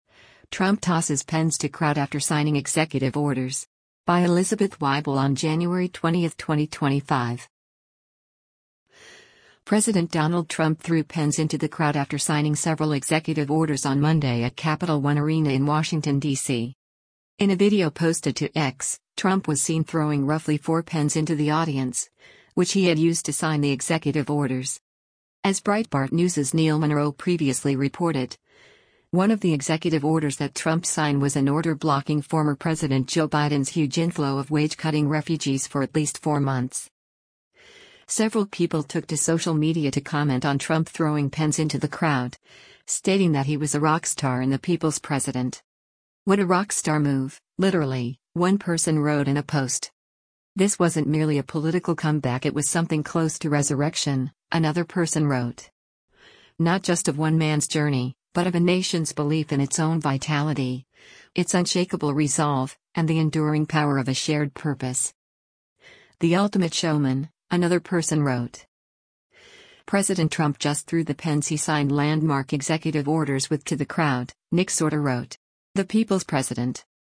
President Donald Trump threw pens into the crowd after signing several executive orders on Monday at Capital One Arena in Washington, DC.